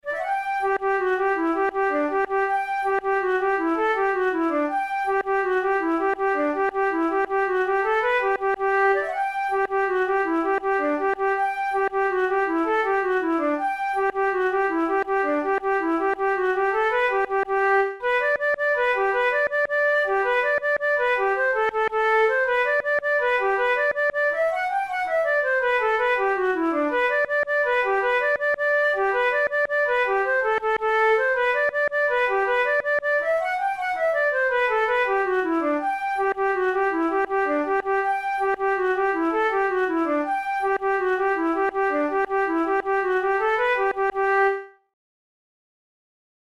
InstrumentationFlute solo
KeyG major
Time signature6/8
Tempo108 BPM
Jigs, Traditional/Folk
Traditional Irish jig